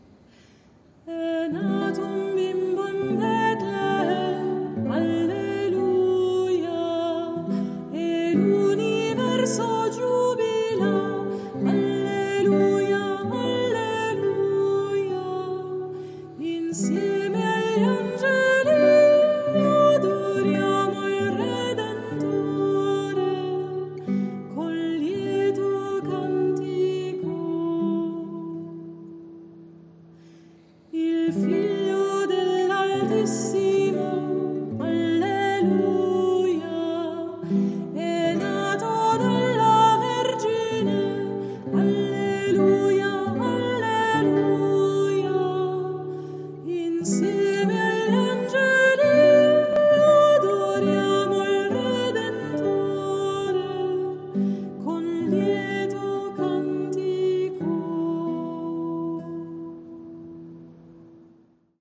versione per chitarra
RN67-E-nato-un-bimbo-in-Bethlem_chitarra.mp3